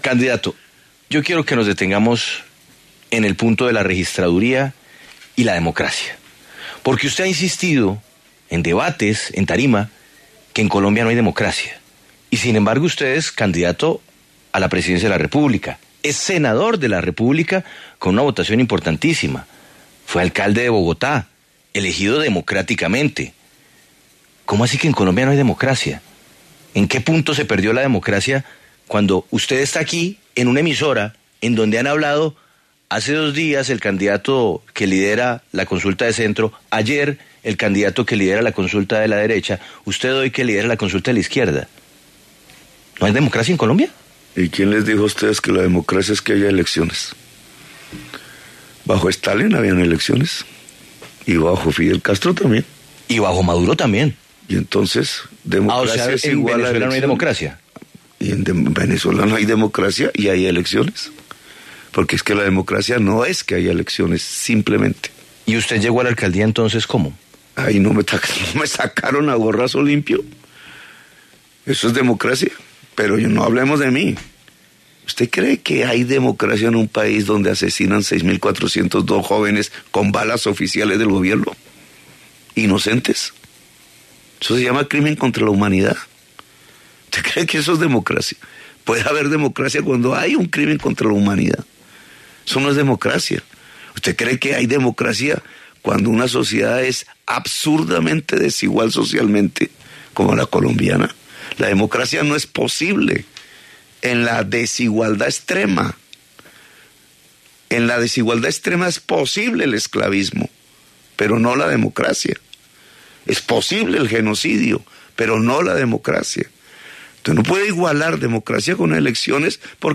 El candidato presidencial Gustavo Petro aseguró en La W que las elecciones en Colombia no son un pase seguro a la democracia.